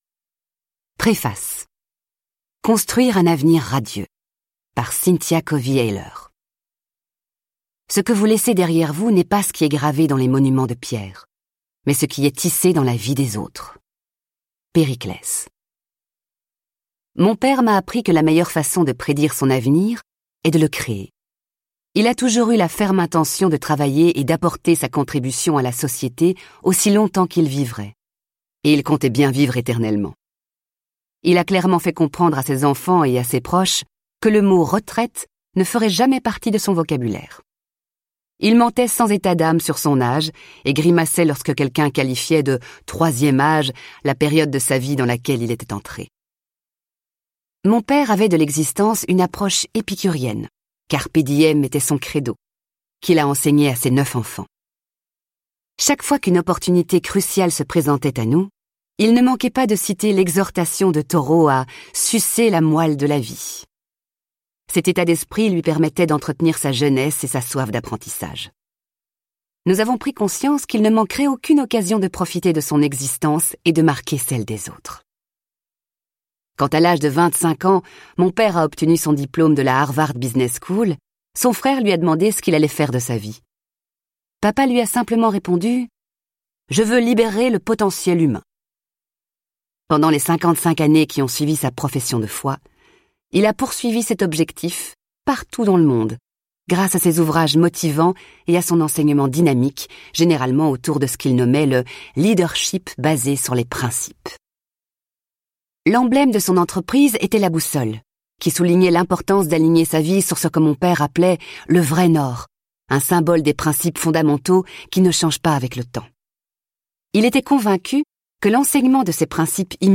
Relever les défis et accepter les opportunités, assurer son succès et sa carrière, élever ses enfants ou prendre soin de ses parents... tout en inspirant les autres et en contribuant à sa propre communauté, voilà la promesse de ce livre audio.